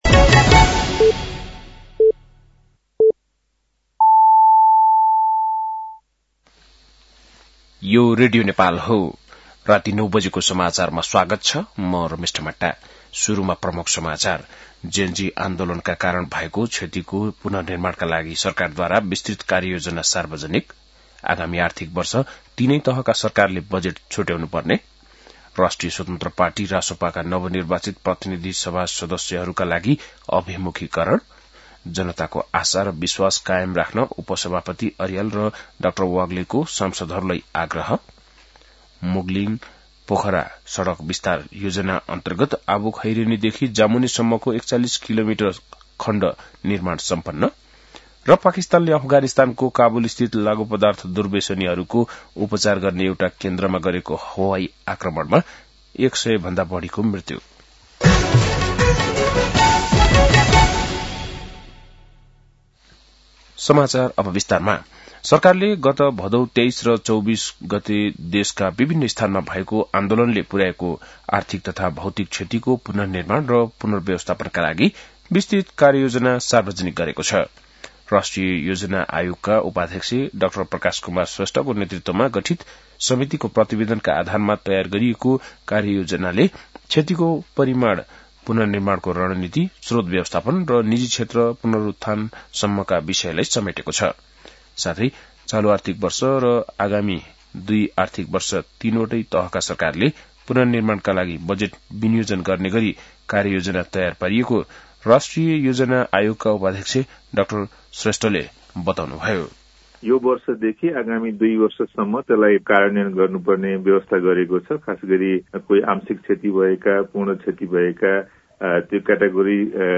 बेलुकी ९ बजेको नेपाली समाचार : ३ चैत , २०८२